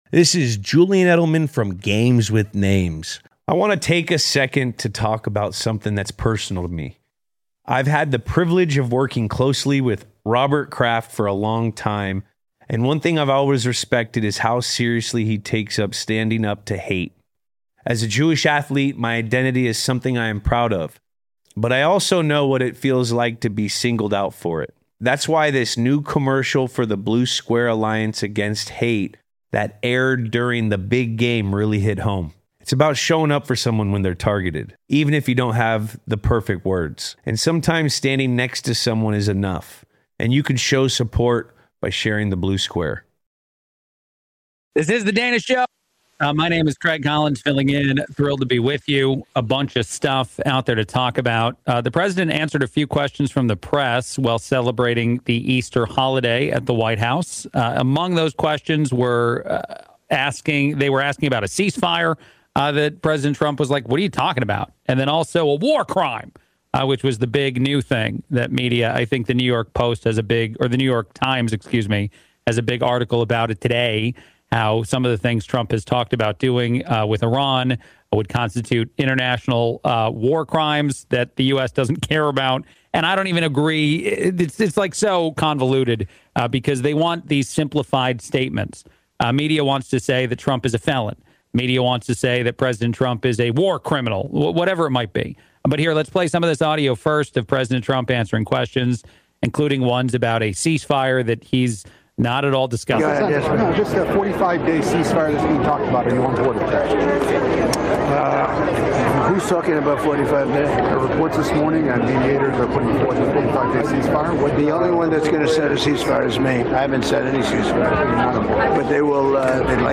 BONUS: President Trump Makes His Most Forceful Iran Threat Yet | Political Commentary Podcast with Dana Loesch
President Trump briefs reporters on updates about Iran.